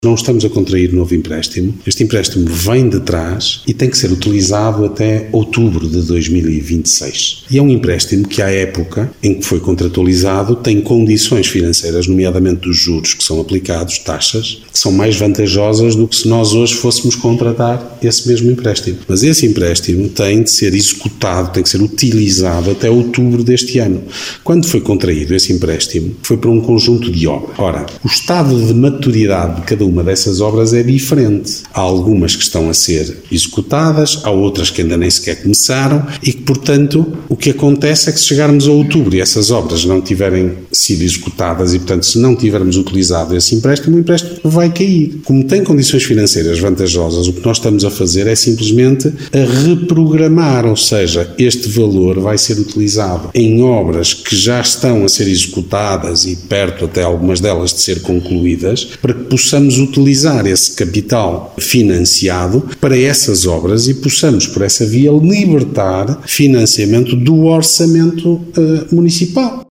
A explicação é dada pelo presidente da Câmara de Guimarães, Ricardo Araújo.